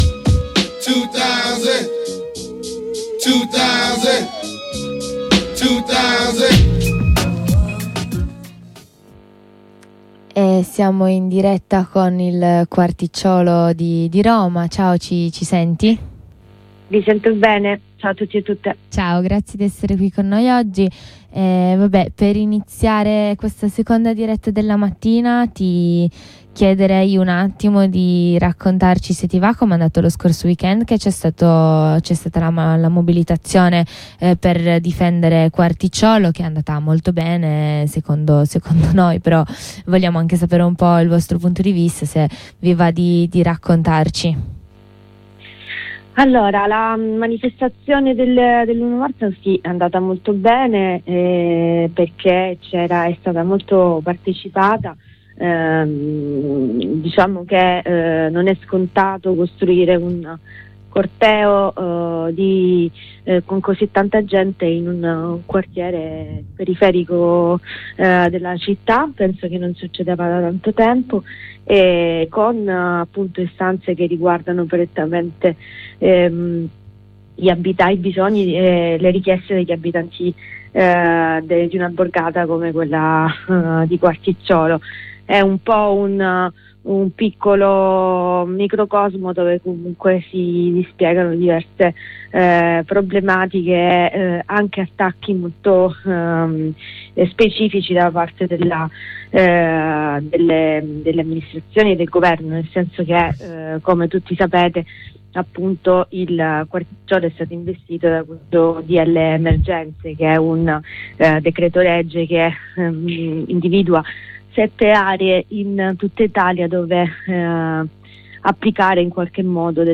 In questa intervista a una compagna di Quarticciolo approfondiamo questi aspetti nel tentativo di complessificare una lettura di una realtà contraddittoria e in cui bisogna stare.